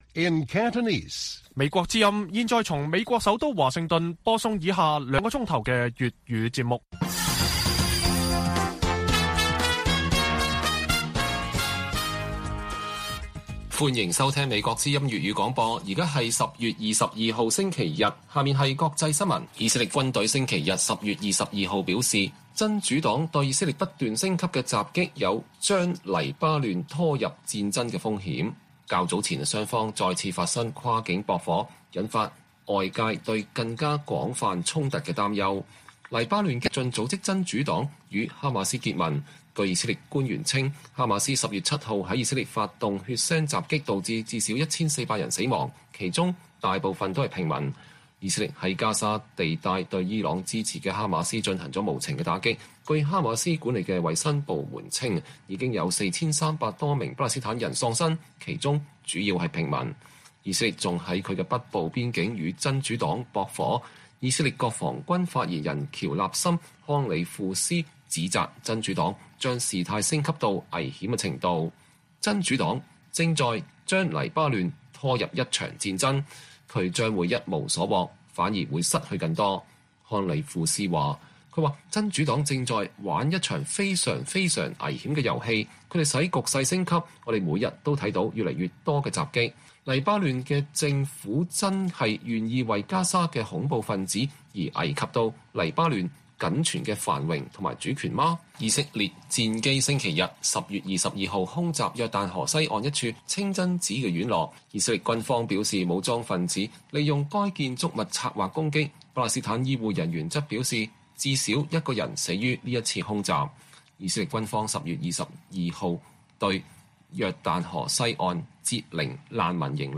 粵語新聞 晚上9-10點 : 以色列軍方：真主黨“正將黎巴嫩拖入戰爭”